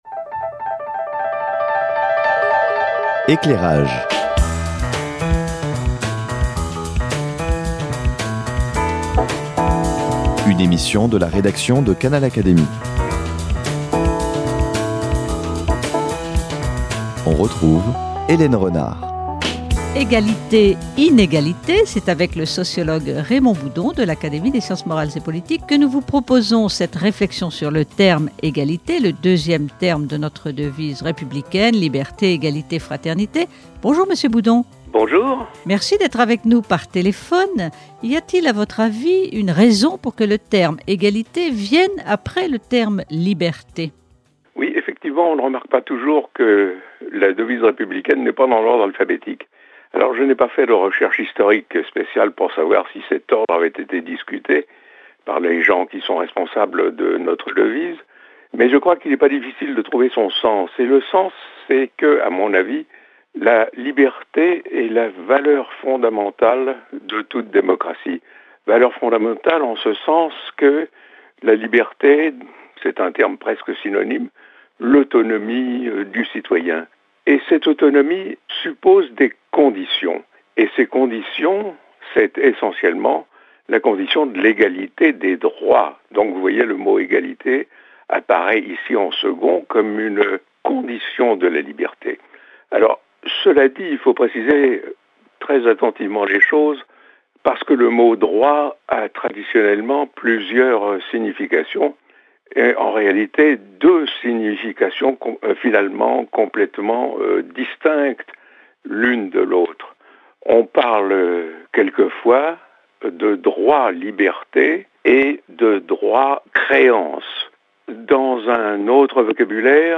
Entretien téléphonique avec Raymond Boudon, dont la réflexion sur les valeurs, sur les notions de juste et d'injuste, font autorité.